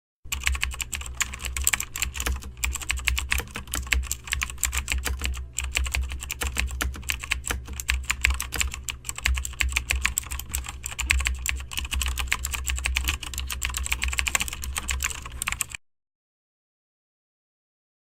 دانلود صدای کیبورد 2 از ساعد نیوز با لینک مستقیم و کیفیت بالا
جلوه های صوتی
برچسب: دانلود آهنگ های افکت صوتی اشیاء دانلود آلبوم صدای تایپ گوشی، کیبورد و ماشین تحریر از افکت صوتی اشیاء